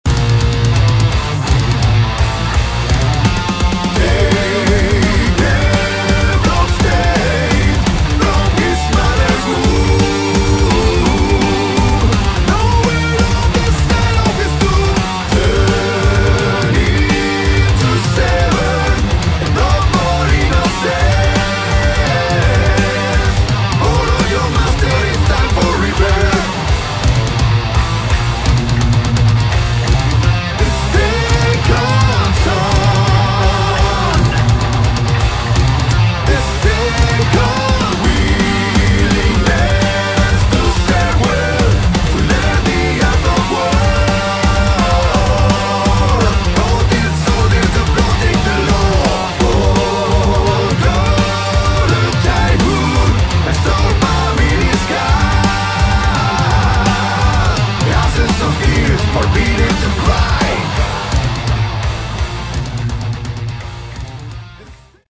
Category: Rock
drums
electric bass
electric guitar
vocals